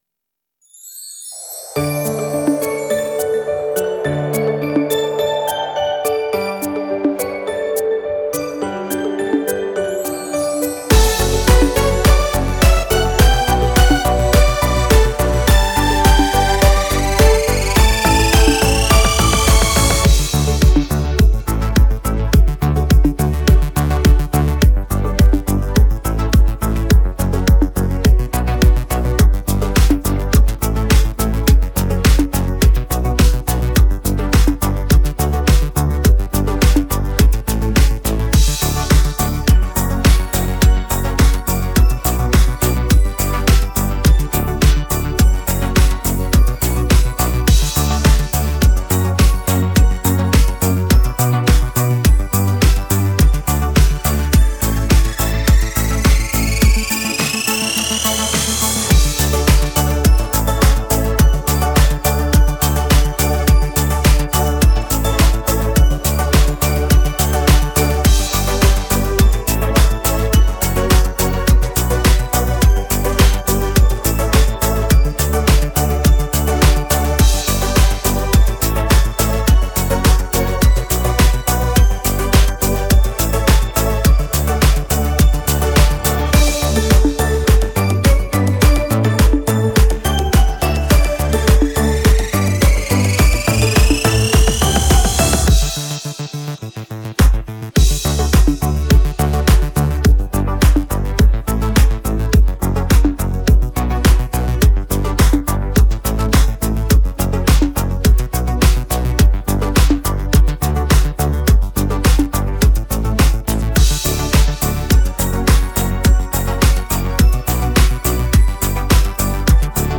Детский музыкальный коллектив
Детская песня